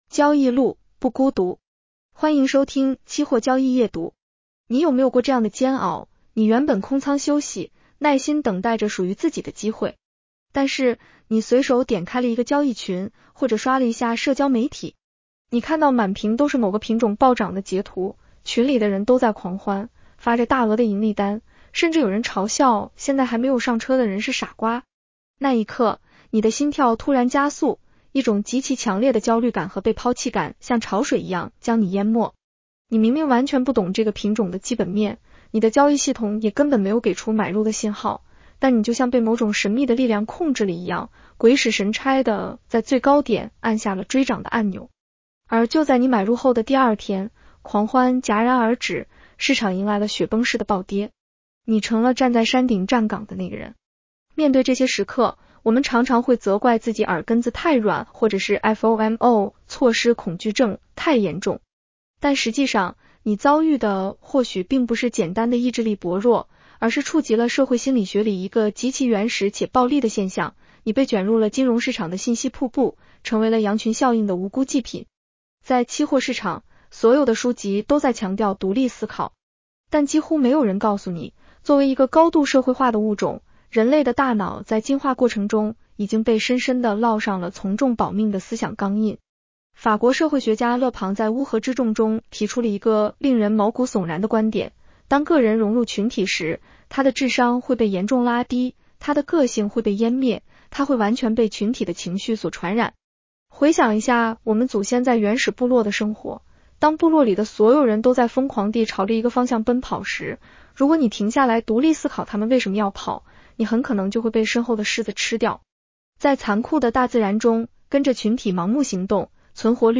（AI生成） 风险提示及免责条款：市场有风险，投资需谨慎。